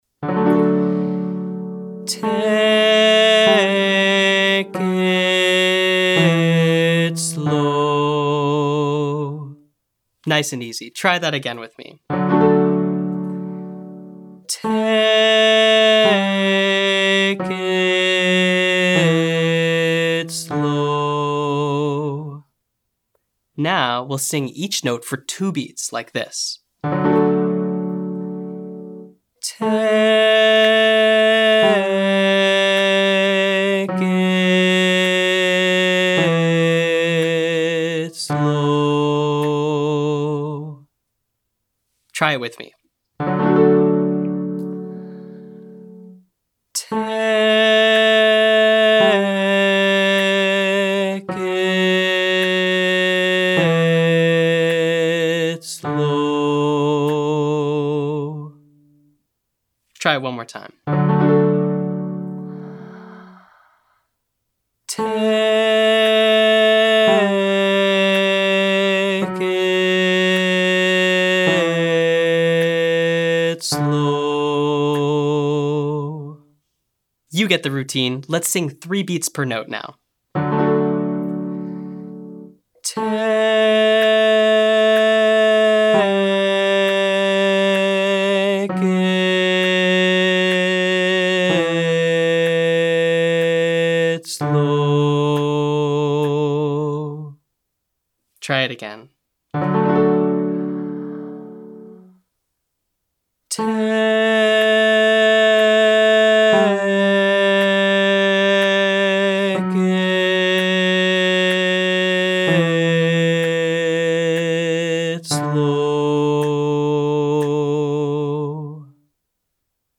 Singing Longer Phrases - Online Singing Lesson